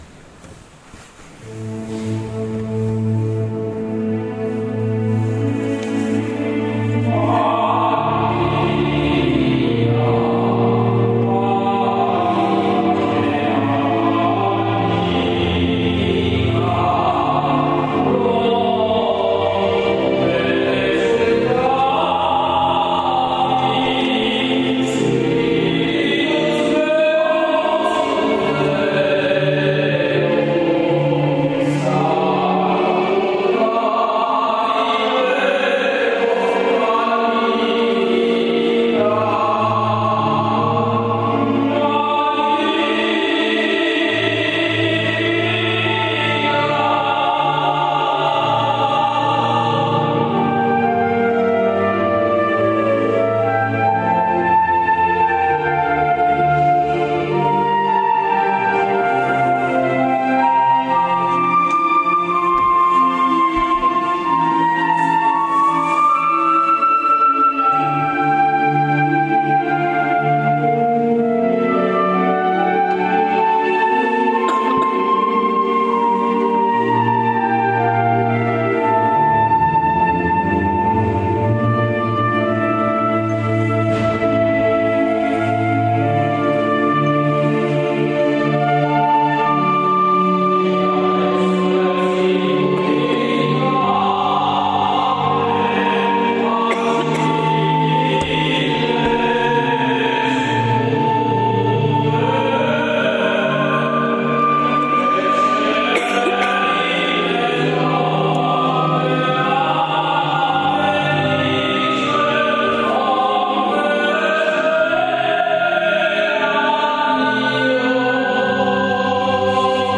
20 settembre concerto NOTE DI SALUTO